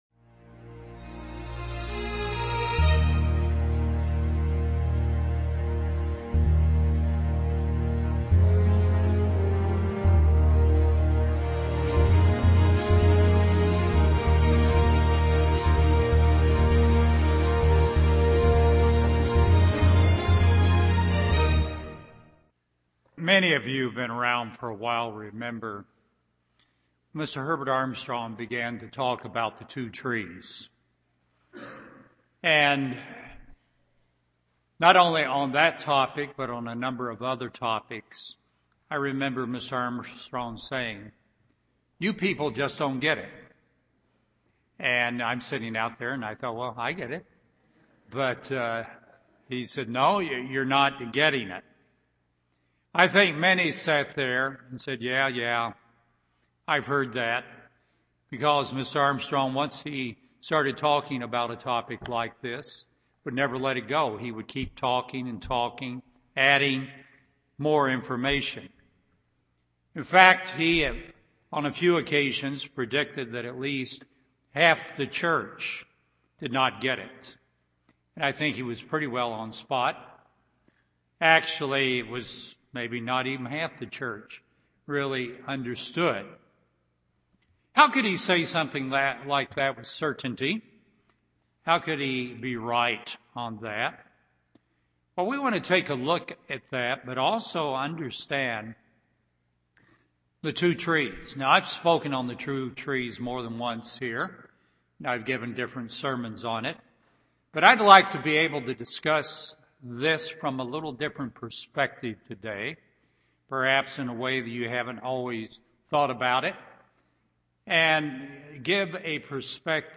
Genesis 3:22 UCG Sermon Transcript This transcript was generated by AI and may contain errors.